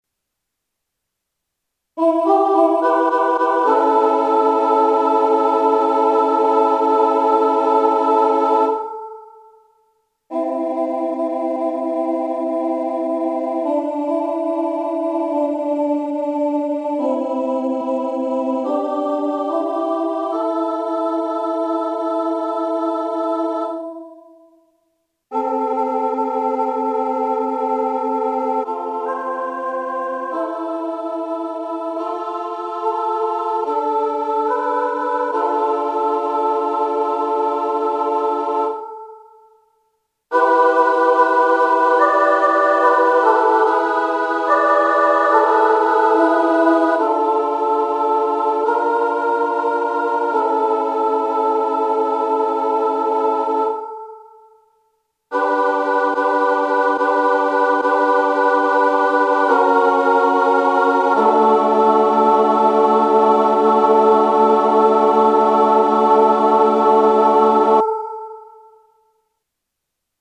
＿＿＿＿＿(ii) （私のイメージの中の）京言葉アクセントを尊重しつつ、
＿＿＿＿＿(iii) 次第に明け行く情景を表現する